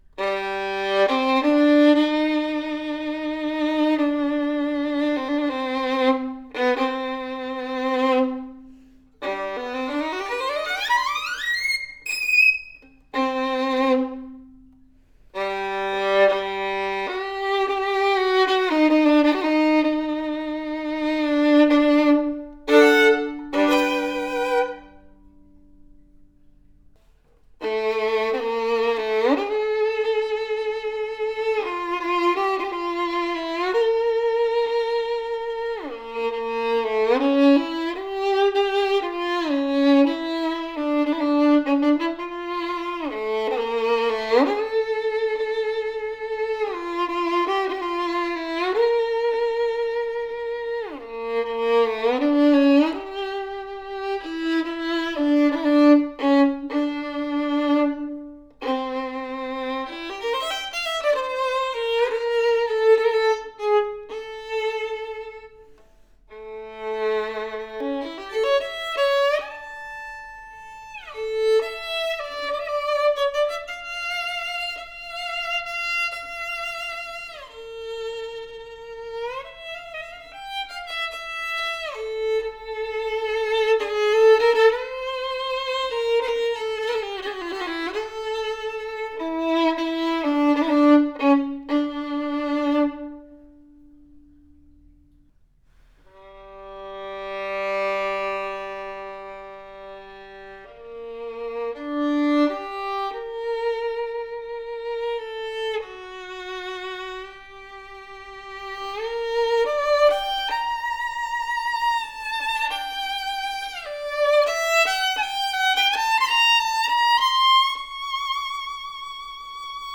A serious “Leduc” del Gesu copy that delivers power and resonant, open tone. Bold and dark sound with sweet mid range, a ringing, projective violin that’s rare for this price range!